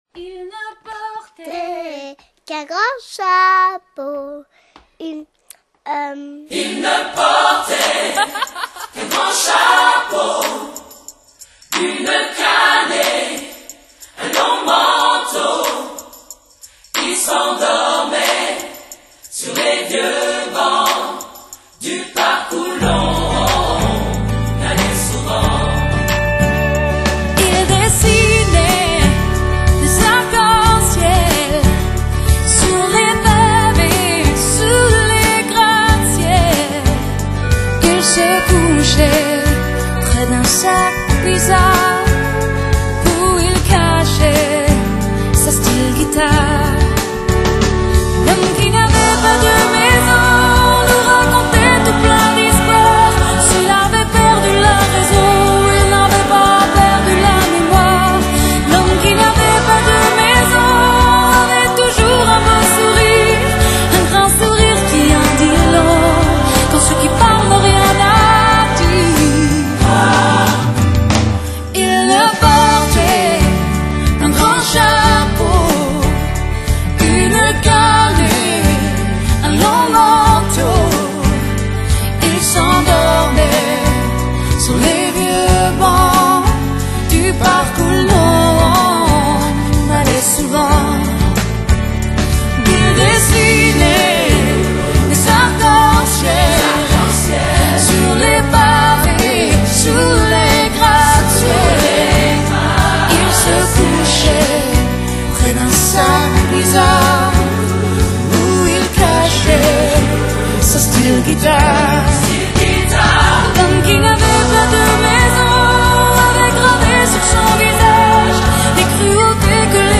그녀의 음색은 신비롭고 묘한 분위기가 ...